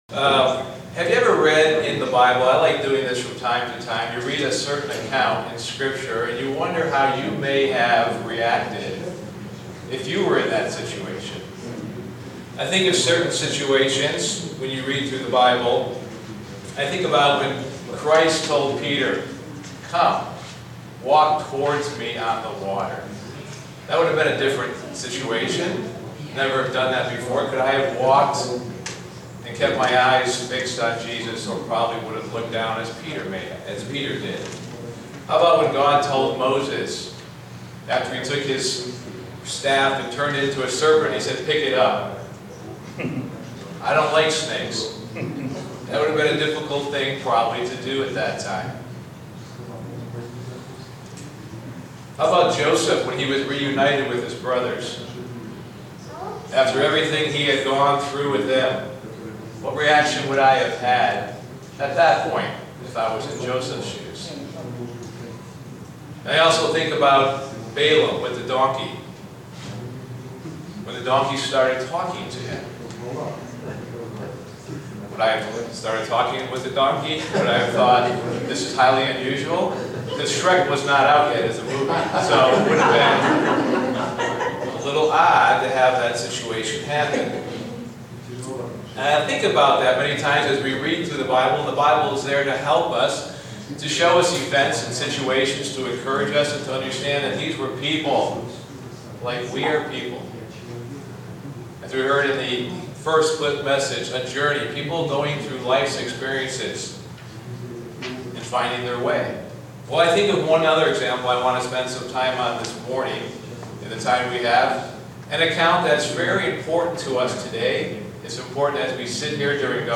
Print The Spirit of Caleb shows a strong belief and faith in God. sermon Studying the bible?